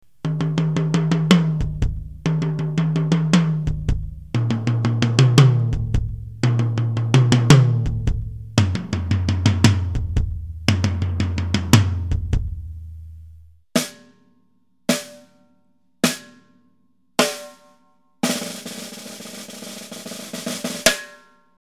j'ai été très étonné par des fûts courts, lors d'un concert, un batteur avait fait des fûts courts à partir de fûts de Tama Grandstar, et ça sonnait vraiment terrible !
petit exemple, il vaut ce qu'il vaut niveau son, mais je trouve que ça rend bien